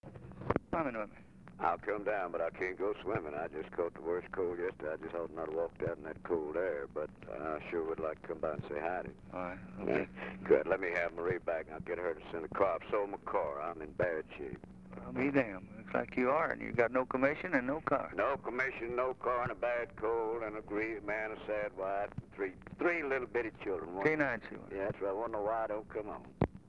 Conversation with HOMER THORNBERRY, December 17, 1963
Secret White House Tapes